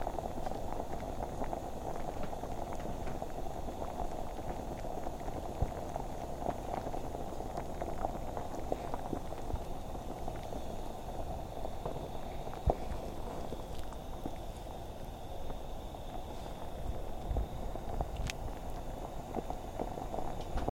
击打厨房锅
描述：击打厨房锅
标签： 厨房 击打 铁锅
声道立体声